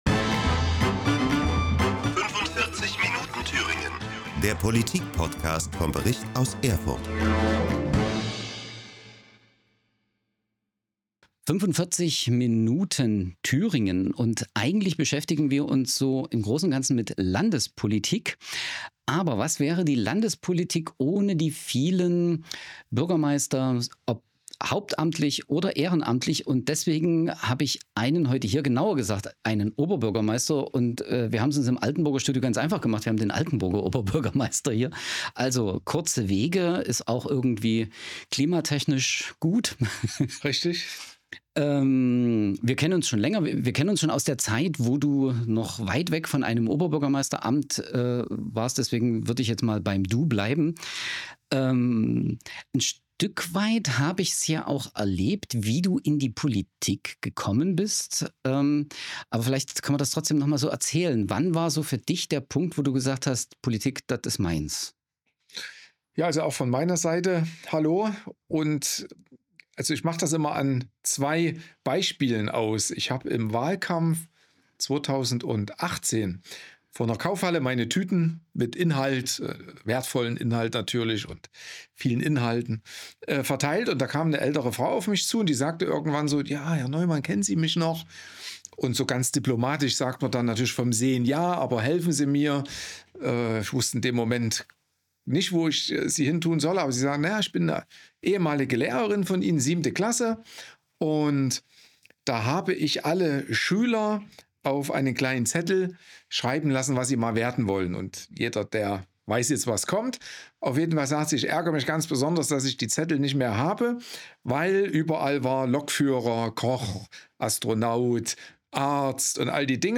Sporttalk im Autohaus Glinicke